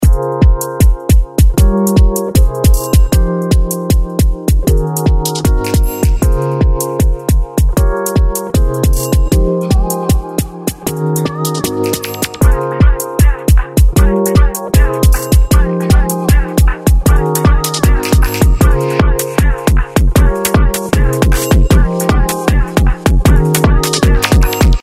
そんな中、一聴ではわかりづらいと思いますがめちゃくちゃ整う「DISCLOSURE – Drum Bus」と、前にブーストさせる「Drum Punch」をレコメンドします。
前半後半で2つのプリセットを順番にかけていますのでチェックしてみてください。